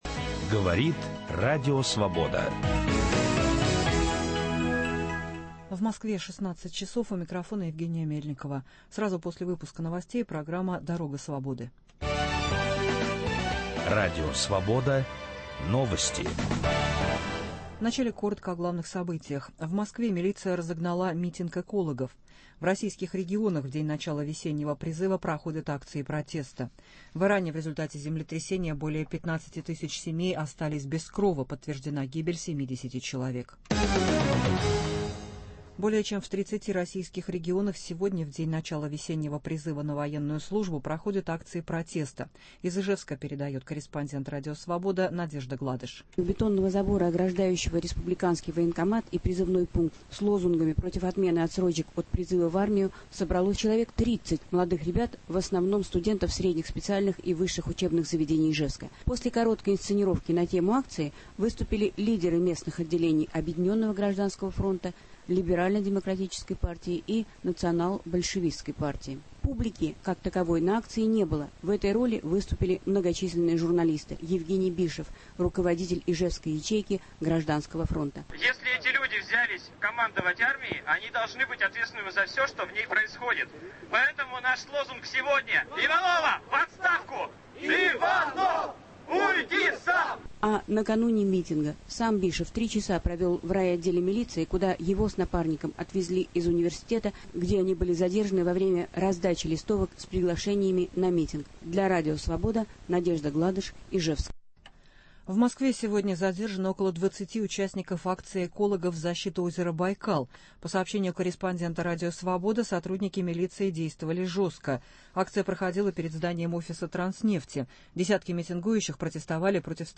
1 апреля начался весенний призыв в армию. О нарушениях прав призывников - региональные репортажи.